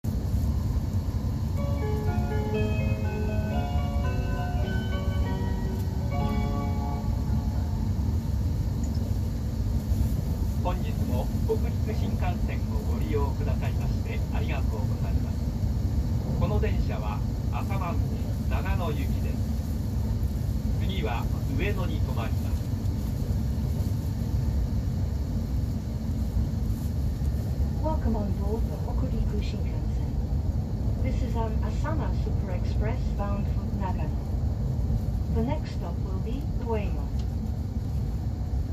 ・E7系車内放送
あさま 東京発車→次は上野
北陸新幹線はJR2社にまたがるので「JR東/西日本をご利用いただきまして…」ではなく「北陸新幹線をご利用いただきまして…」という言い回しになっているのが特徴です。尚、下りの詳細な案内放送は上野発車後に流れるので、東京発車後の放送はこざっぱりしています。